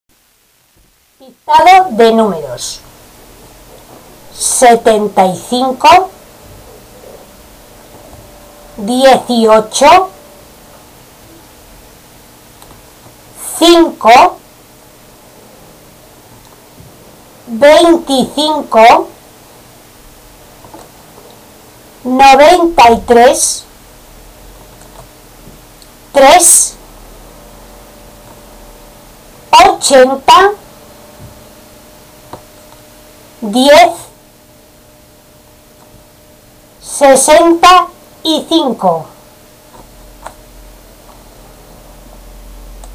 Dictado_de_numeros.mp3